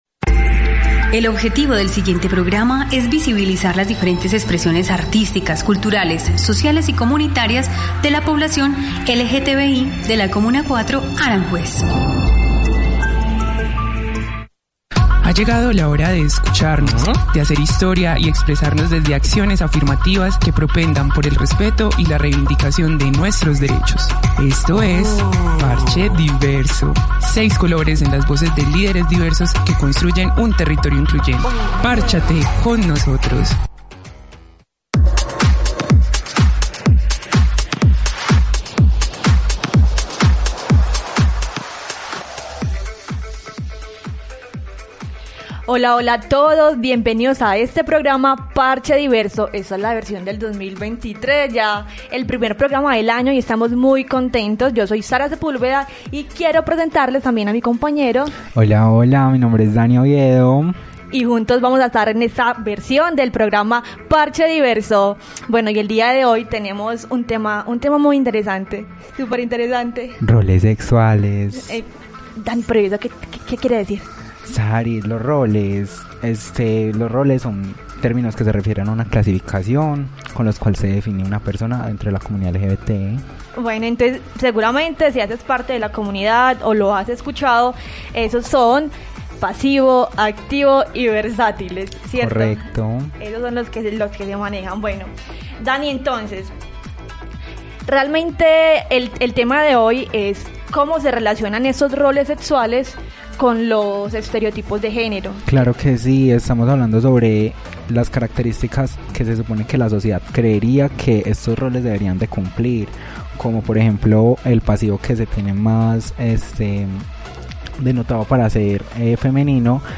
Únete a la conversación en nuestro programa radial y participa escribiendo tus comentarios Contenido